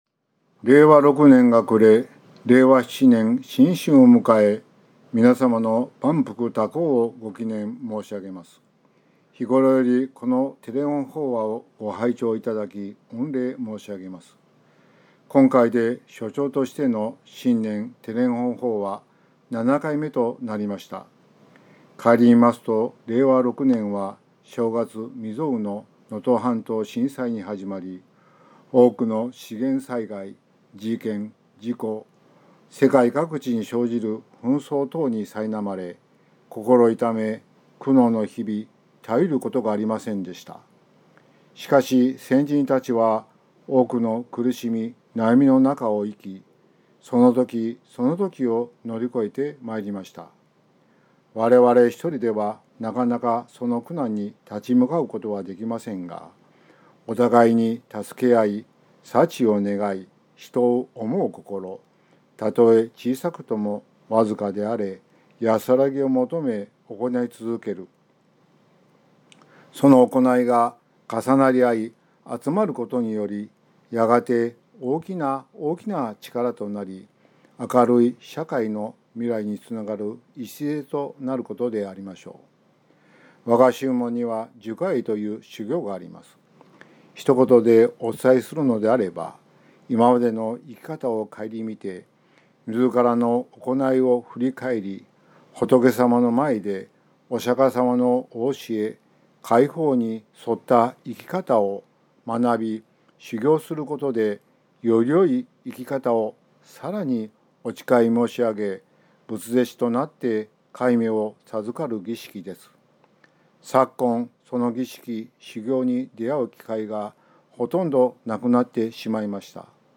曹洞宗岐阜県宗務所 > テレフォン法話 > 「善きご縁が結ぶ仏と出会う―授戒会」